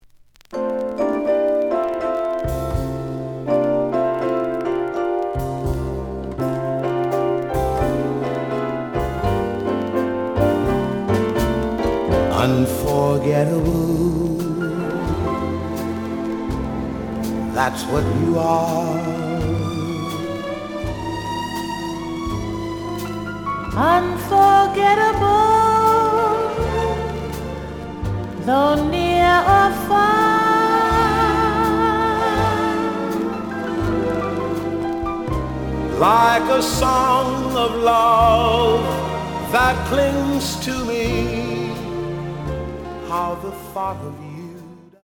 The audio sample is recorded from the actual item.
●Genre: Vocal Jazz
Slight edge warp. But doesn't affect playing. Plays good.)